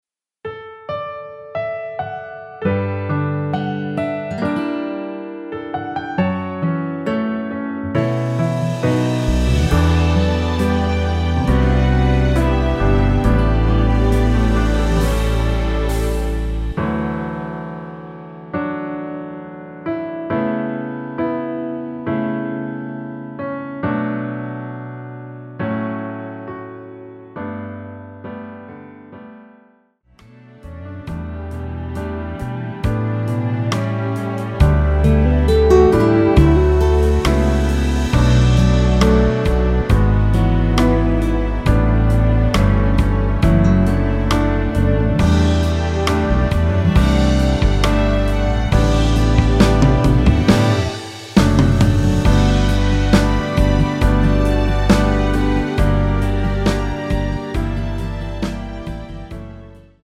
앨범 | O.S.T
◈ 곡명 옆 (-1)은 반음 내림, (+1)은 반음 올림 입니다.
앞부분30초, 뒷부분30초씩 편집해서 올려 드리고 있습니다.